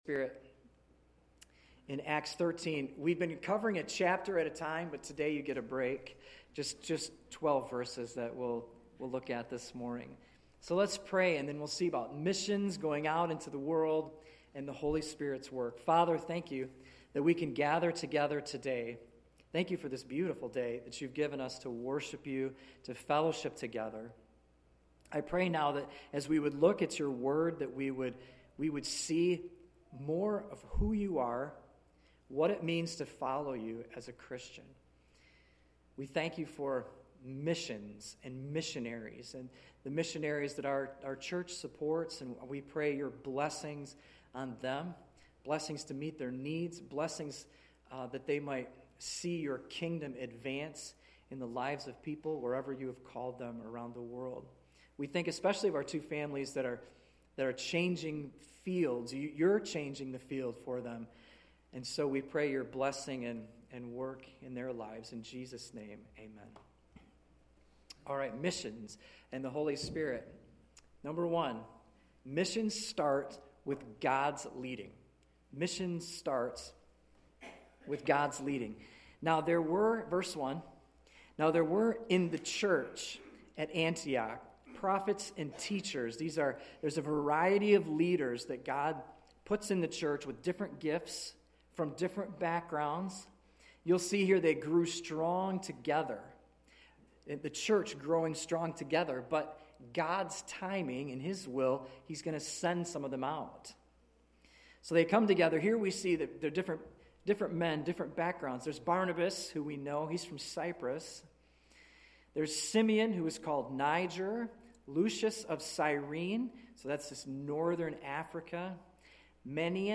Sunday-Morning-Service-June-8-2025.mp3